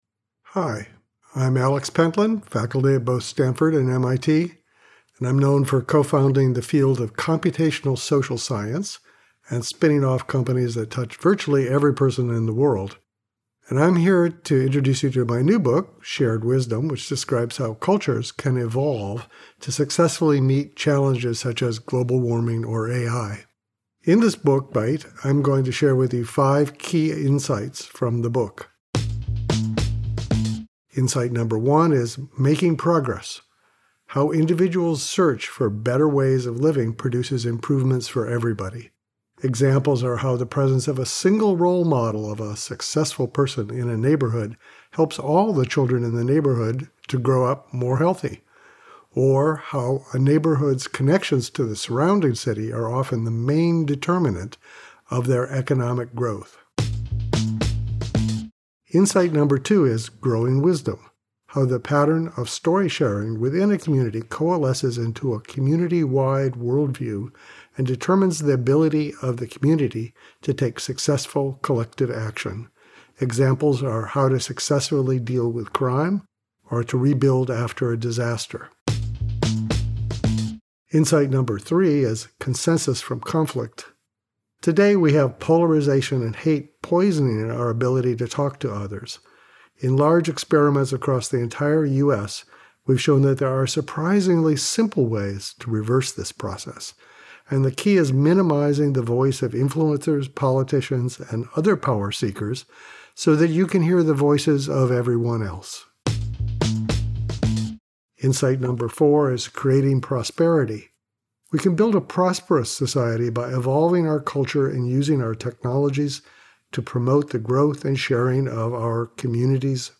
Listen to the audio version of this Book Bite—read by Alex himself—below, or in the Next Big Idea App.